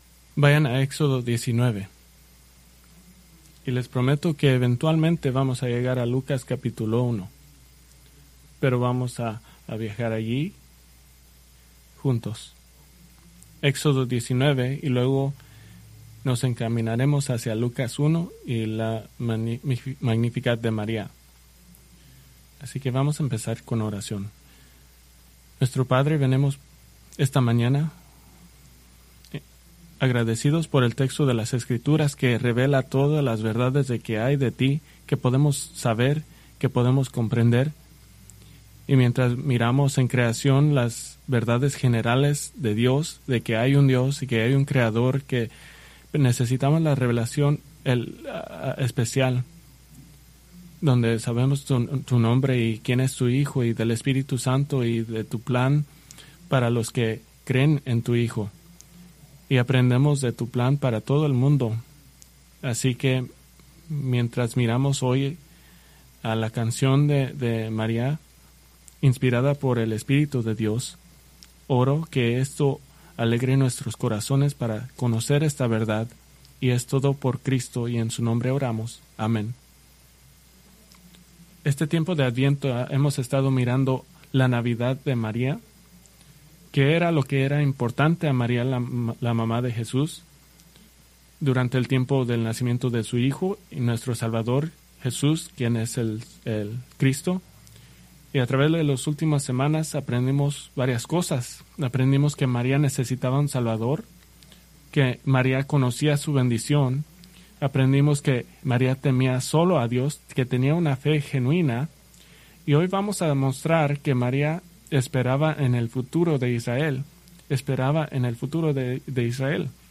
Preached December 21, 2025 from Lucas 1:54-55